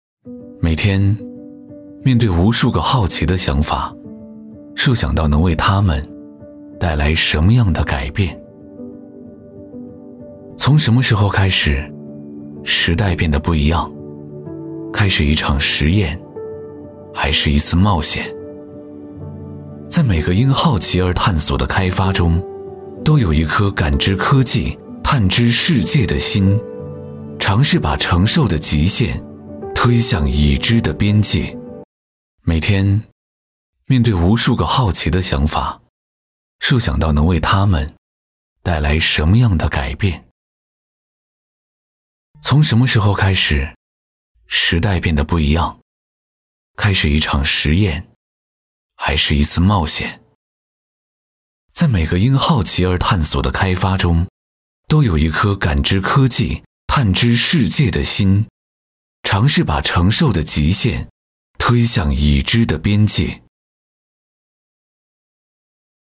190男-沧桑大气
特点：大气浑厚 稳重磁性 激情力度 成熟厚重
宣传片——科技感【素人 高级 洋气】.mp3